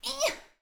SFX_Battle_Vesna_Defense_03.wav